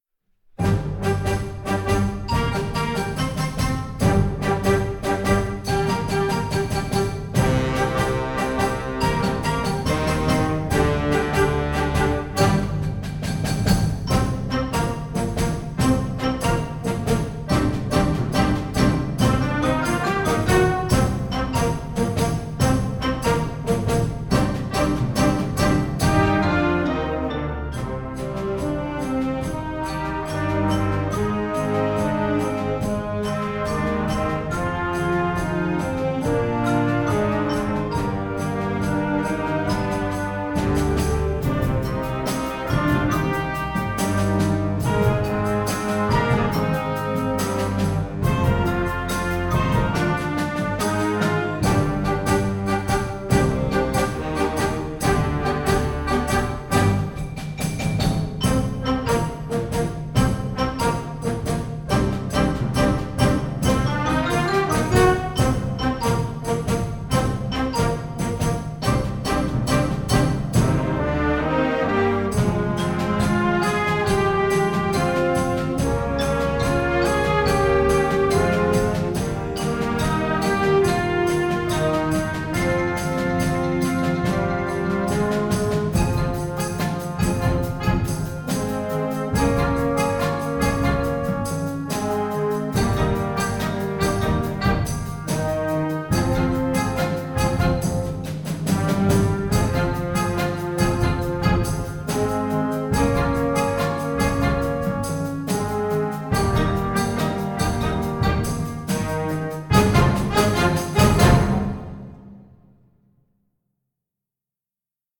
Gattung: Moderner Titel für Jugendblasorchester
Besetzung: Blasorchester